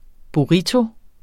Udtale [ buˈʁito ]